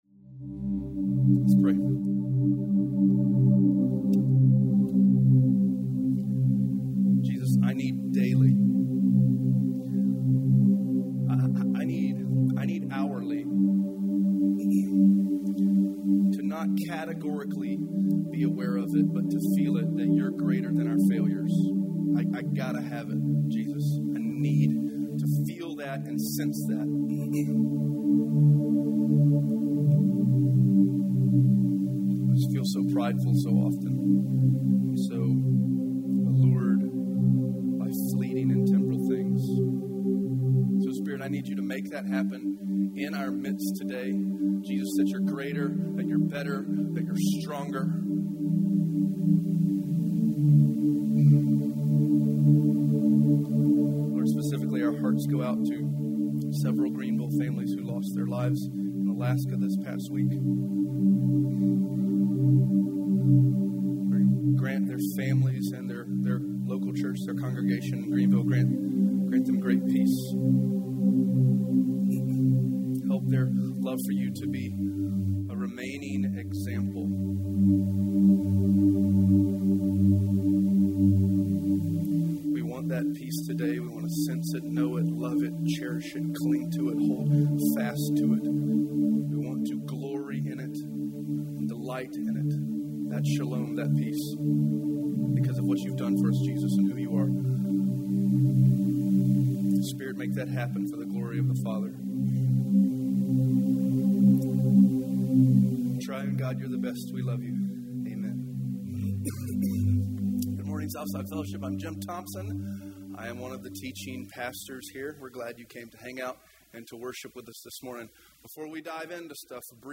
A Sermon About Blood (Heb. 9 11-28) | Fellowship Greenville | Fellowship Greenville